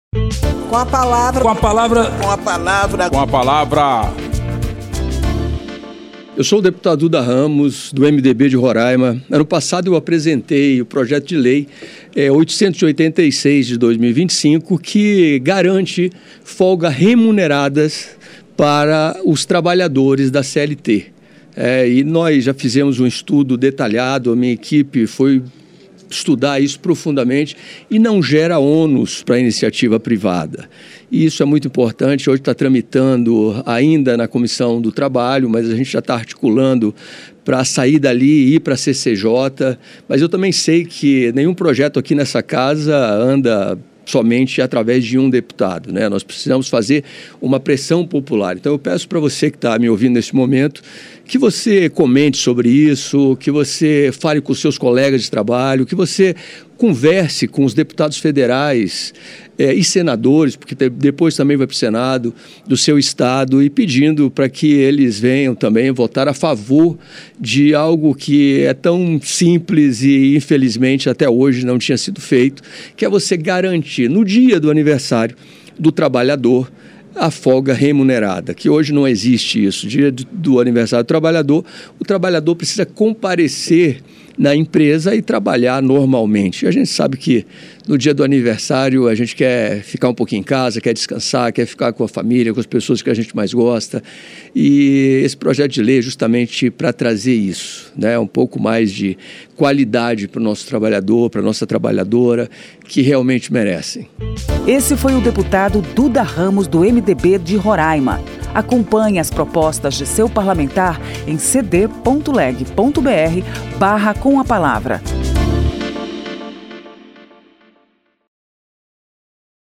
Espaço aberto para que cada parlamentar apresente aos ouvintes suas propostas legislativas